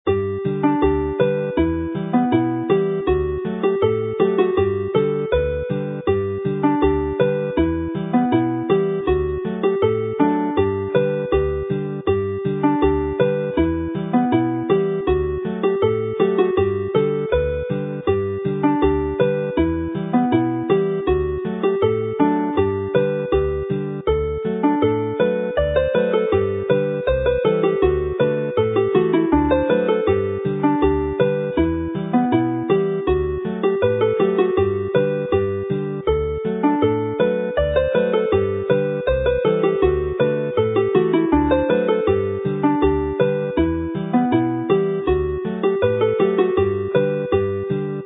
Uchder Cader Idris (in G)
The Height of Cader Idris runs well as a dance tune; its meoldy contrasts with Agoriad y Blodau but relates directly to the third tune in the set.